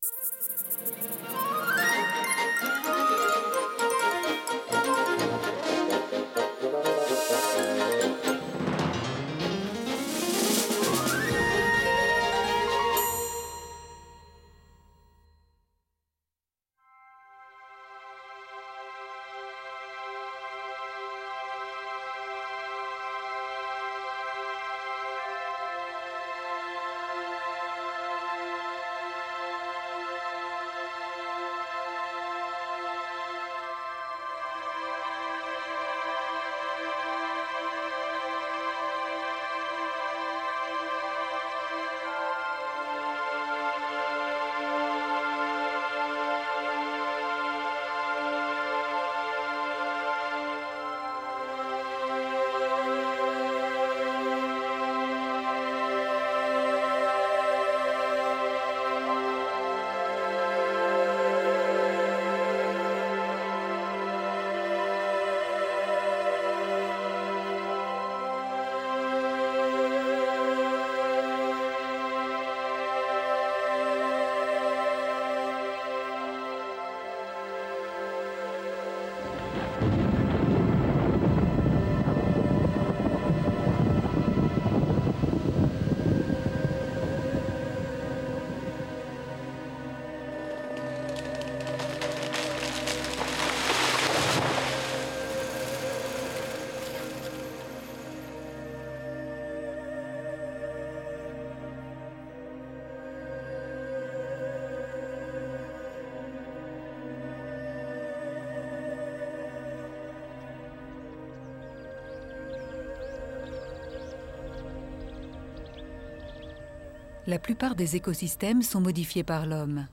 Réalisé dans la réserve naturelle des marais de Lavours (Ain) ainsi qu'en laboratoire à l'Université du Maine (Le Mans, Sarthe), ce documentaire scientifique à vocation pédagogique traite de l'écologie et de la biologie de la conservation des papillons Maculinea des zones humides (Azuré de la sanguisorbe, Azuré des Mouillères, Azuré des Paluds). Ce document est innovant en la matière puisqu'il aborde l'inféodation du papillon à une plante spécifique et l'adaptation à une fourmi hôte.